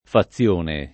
fazione [ fa ZZL1 ne ] s. f.